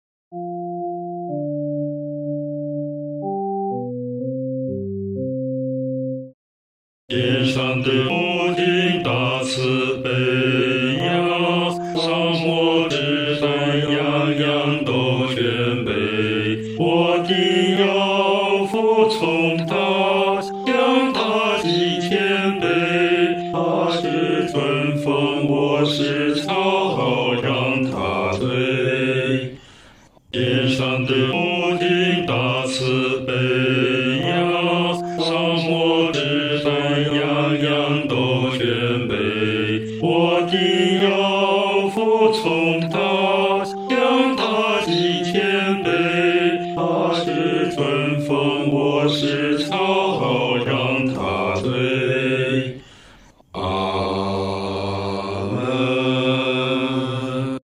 男低
本首圣诗由网上圣诗班 (南京）录制